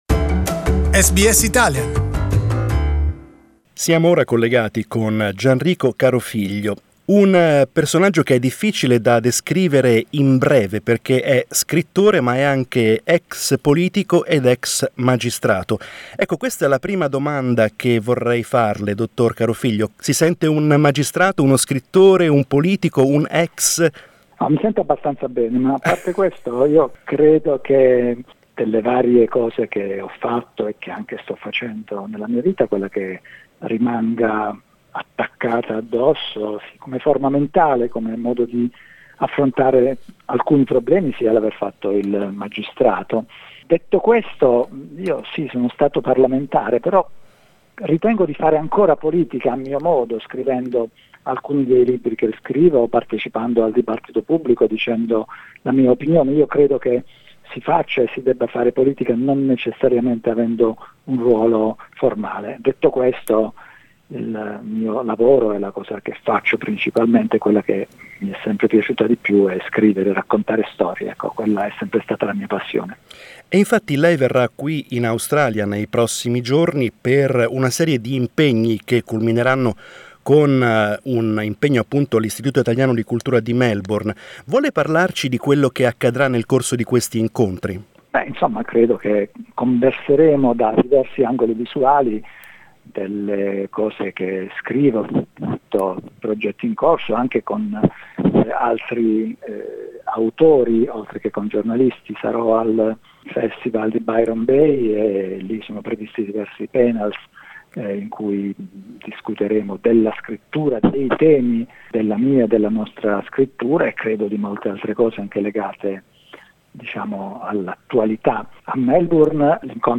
We interviewed the author just days before he boarded a plane to Australia.